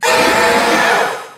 PLA cries